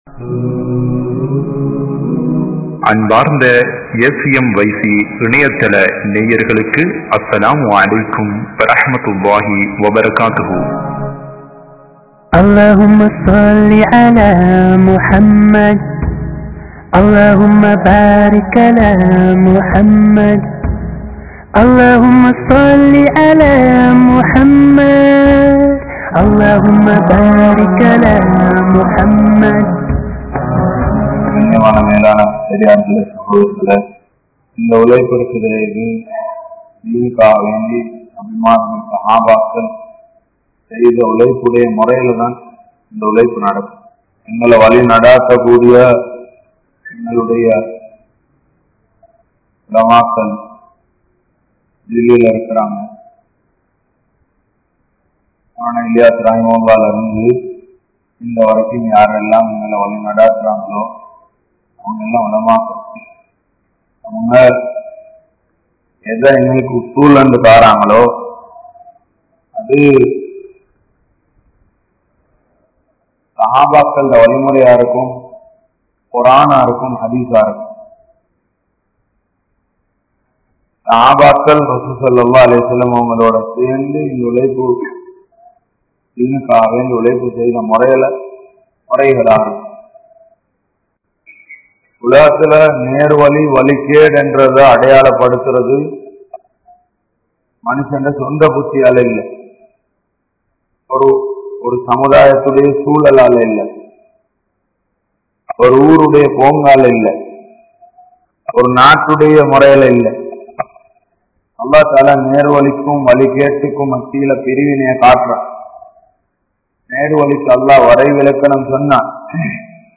Sahabaakkal Entraal Yaar? (ஸஹாபாக்கள் என்றால் யார்?) | Audio Bayans | All Ceylon Muslim Youth Community | Addalaichenai